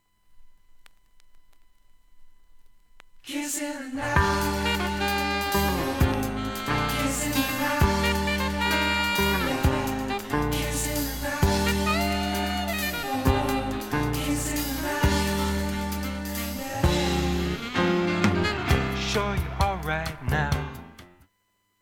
盤面きれいで音質良好全曲試聴済み。
A-1始めにスレで、6回ほどプツ出ますが